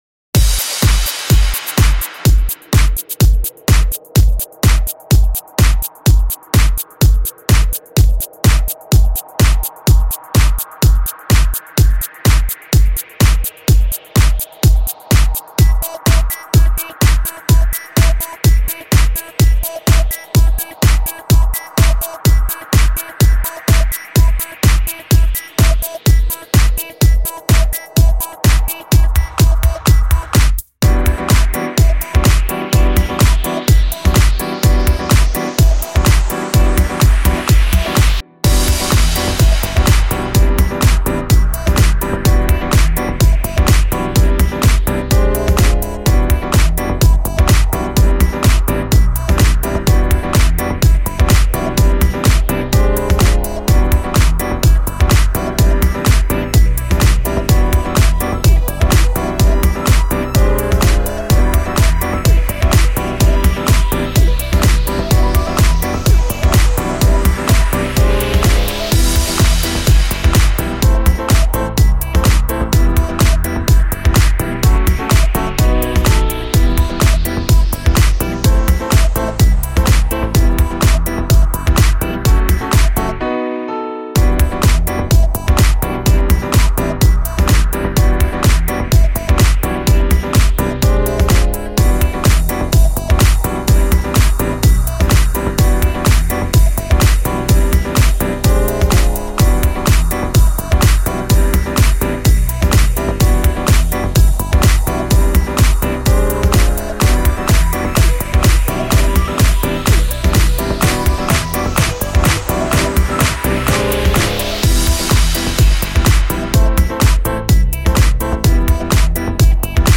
[House Music] He need a girlfriend | 리드머 - 대한민국 힙합/알앤비 미디어